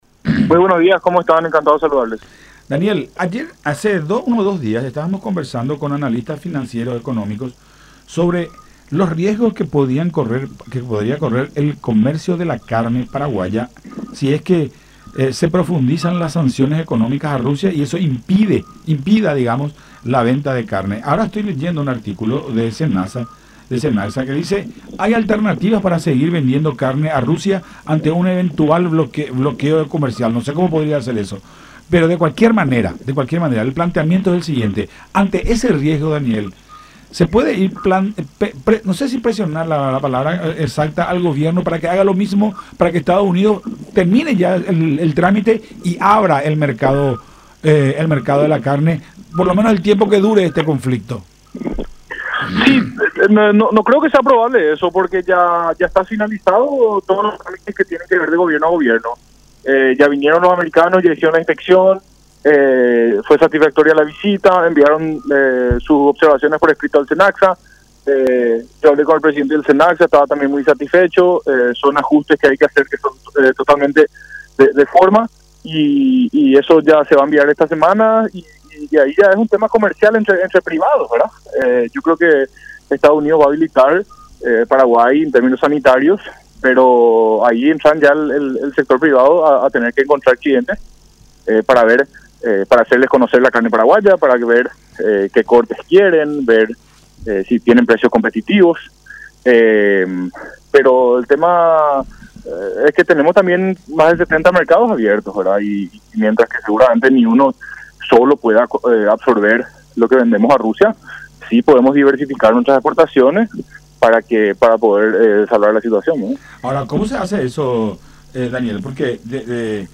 en diálogo con Todas Las Voces por La Unión.